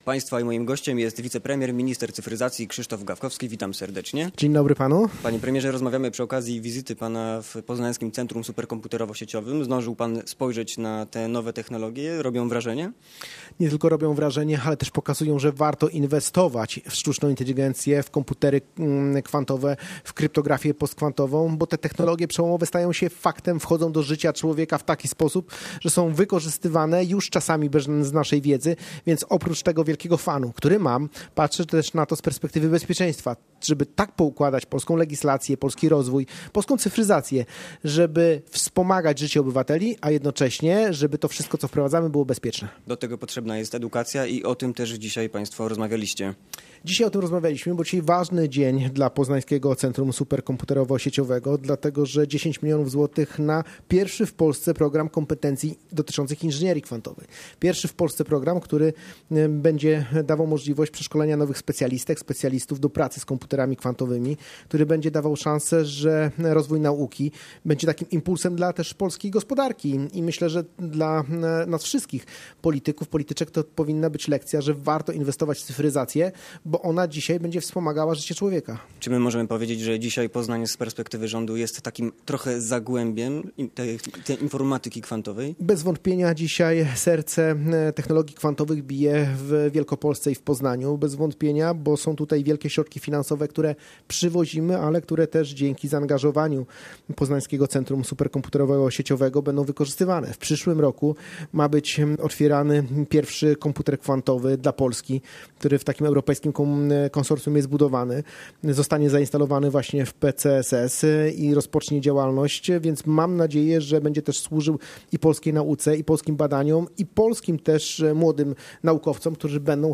Popołudniowa rozmowa Radia Poznań - Krzysztof Gawkowski